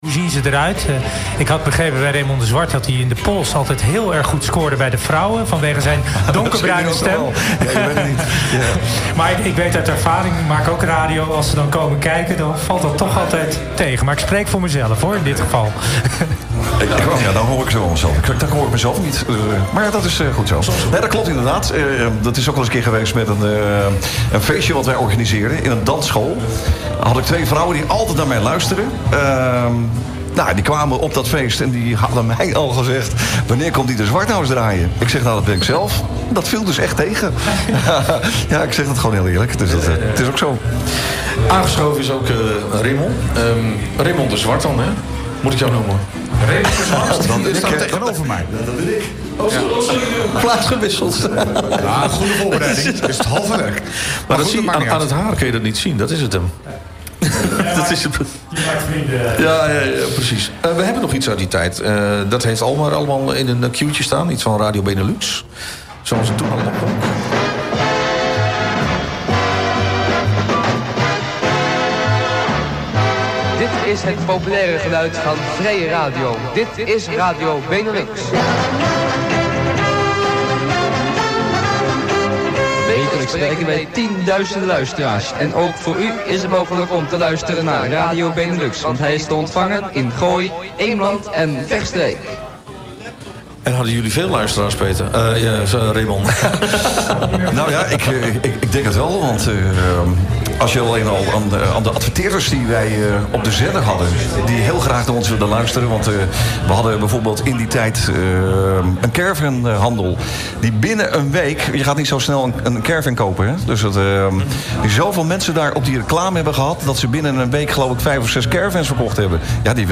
Een interview vanuit een speciale uitzending vanaf de Kerkbrink in Hilversum. In het kader van de Dutch Media Week en 100 Jaar Radio gingen wij terug in de tijd naar de momenten, waar illegale zenders in ‘t Gooi overal de kop op staken en razend populair waren.